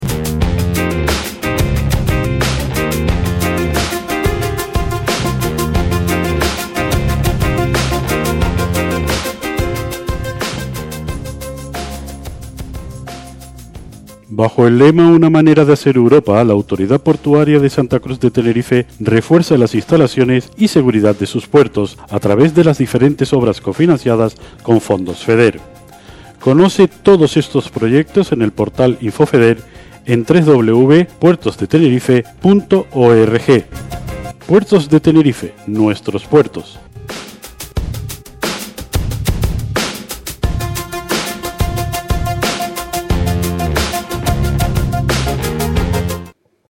Cuña publicitaria – Radio Muelle
Cuna-publicitaria-Radio-Muelle.mp3